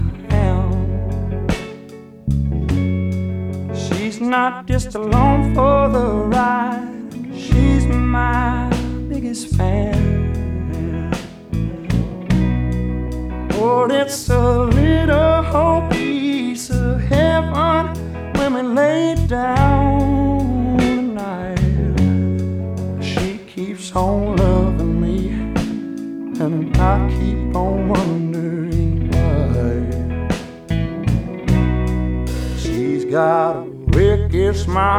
# Americana